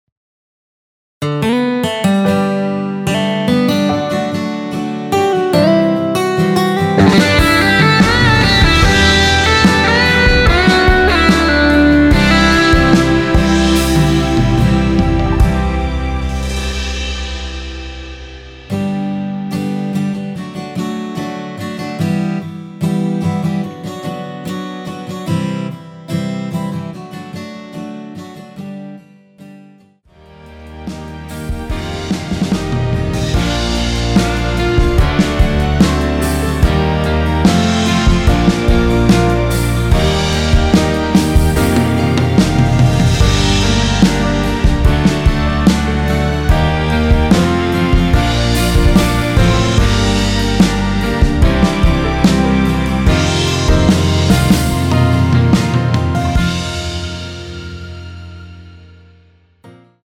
원키(2절 삭제) MR입니다.
앞부분30초, 뒷부분30초씩 편집해서 올려 드리고 있습니다.